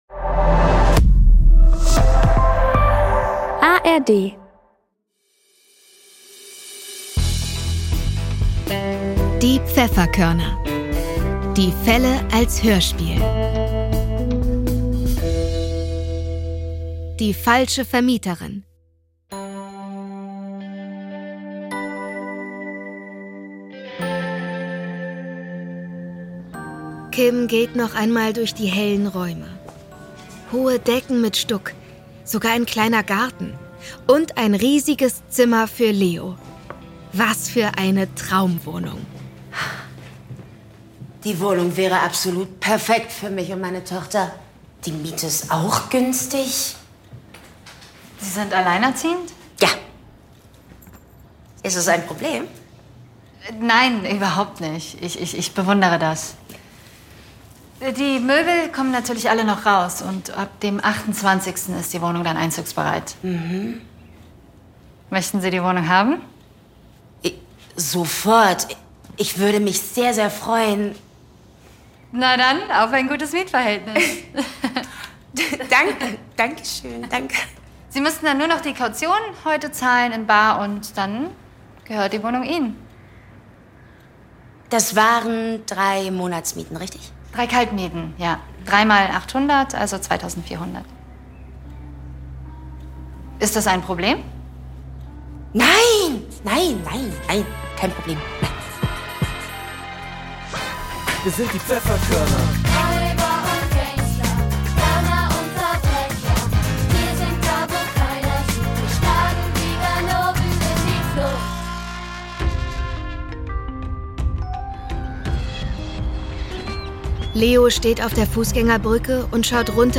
Folge 20 - Die falsche Vermieterin ~ Die Pfefferkörner - Die Fälle als Hörspiel Podcast